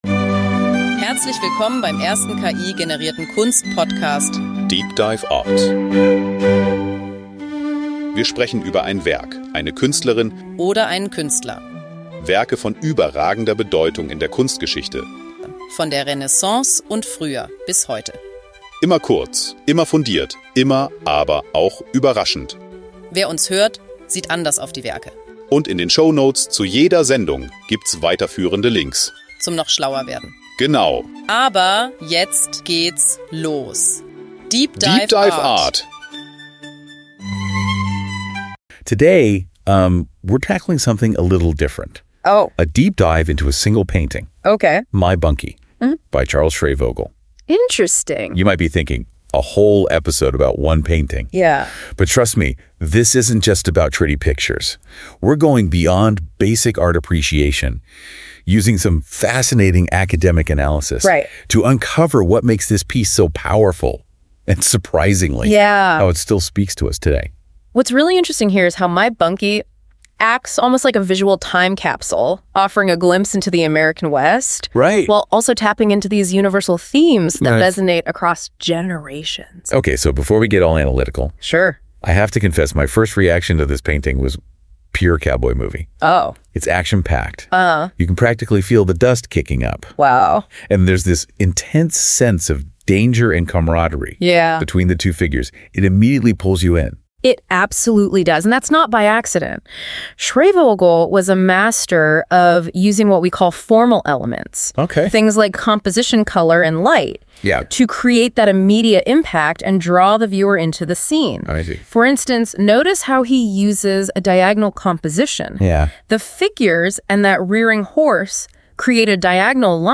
It also highlights the importance of relationships and teamwork, as well as the timeless messages that can be derived from analyzing the painting. deep dive art is the first fully Ki-generated art podcast.
The two hosts, the music, the episode photo, everything. dda is for everyone who has little time but wants a lot of knowledge.